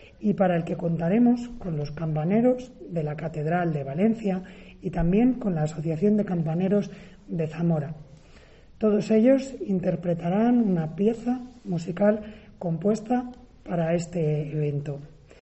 Será con un programa de 10 actividades, siempre teniendo en cuenta la situación sanitaria. Entre ellas destaca el concierto de campanas que tendrá lugar el próximo 10 de octubre, para el que se ha compuesto un tema especial. A este concierto se sumarán ocho campanarios de la ciudad, con la colaboración de 20 personas, como ha explicado la teniente de alcalde de Turismo, Sonsoles Prieto.